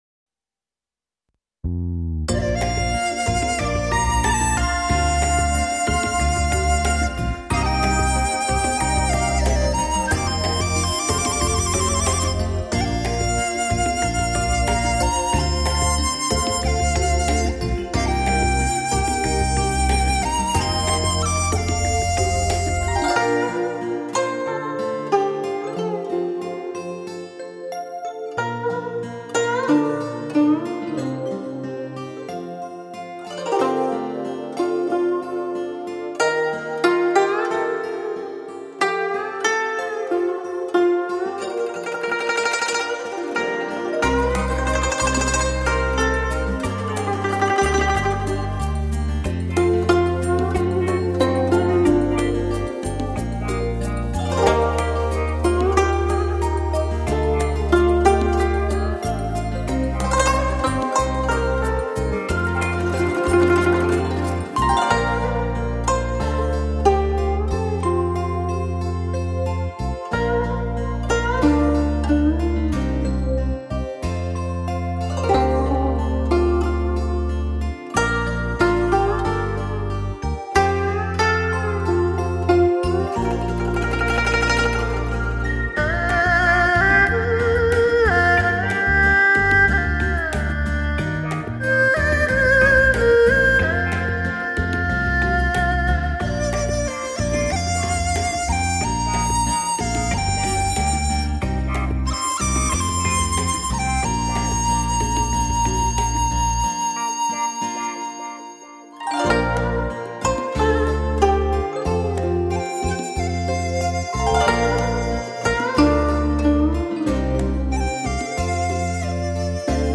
唱片类型：民族音乐
专辑语种：纯音乐
品味古筝乐韵情怀，陶醉美人好景良辰。